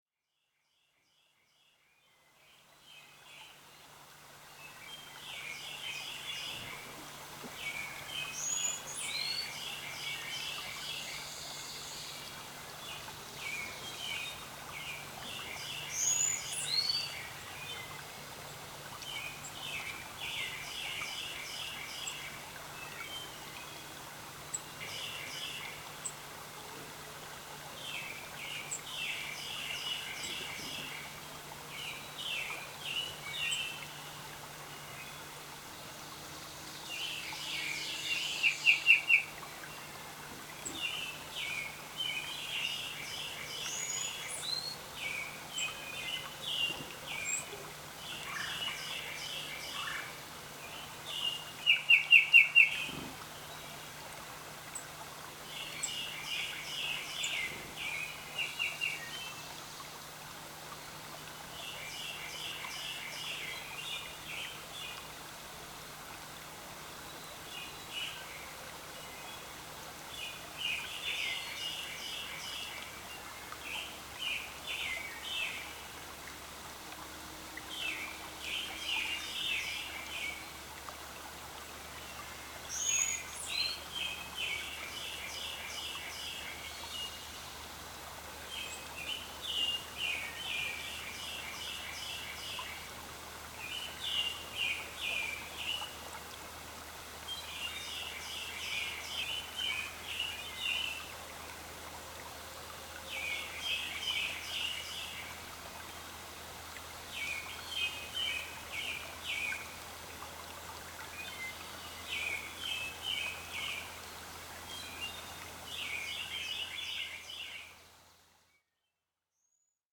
Morning Songbirds of Roberts Mountain
Morning_Songbirds_CD_Sample_0.mp3